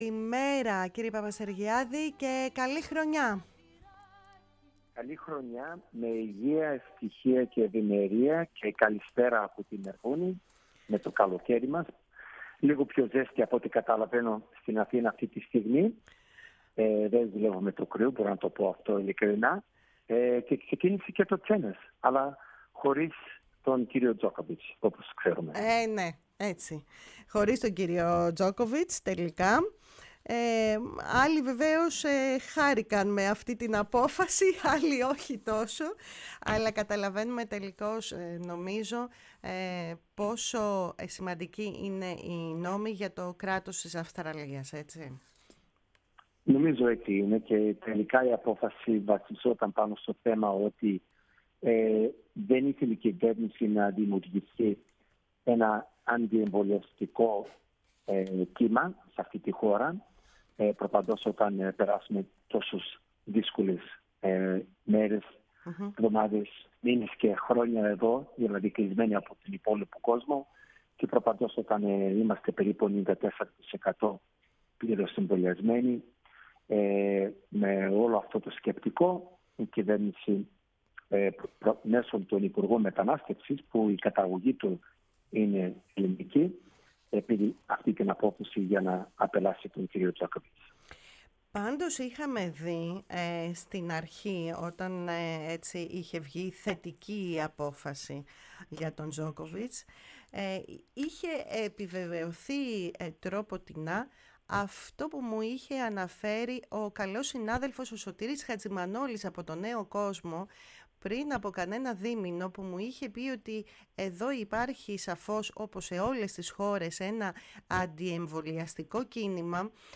φιλοξενήθηκε τηλεφωνικώς